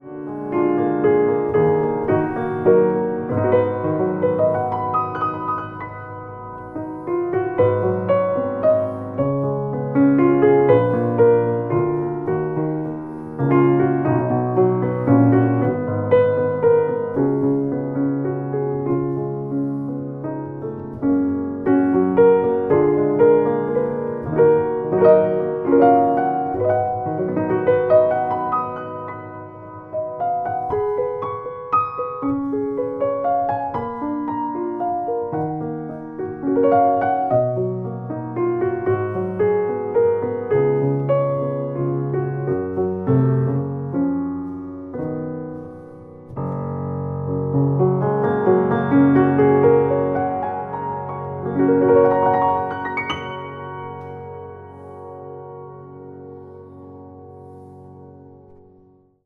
Prachtige totaal gerestaureerde C. Bechstein Model M vleugel.